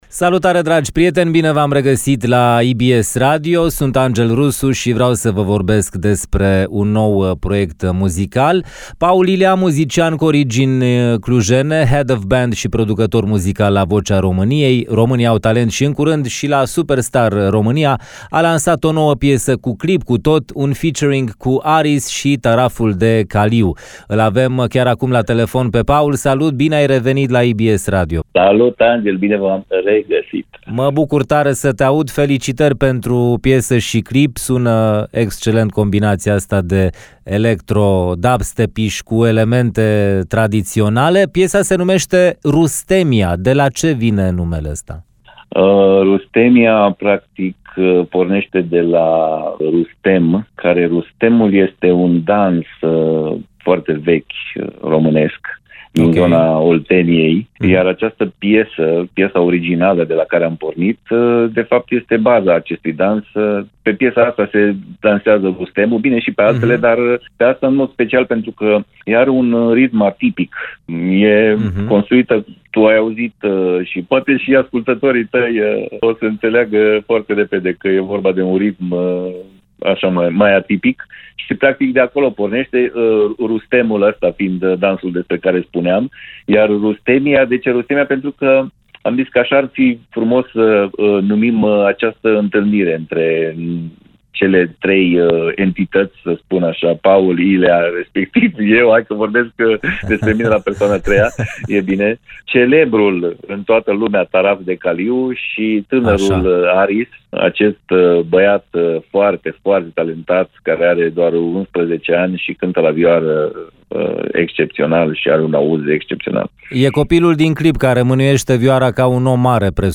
interviul integral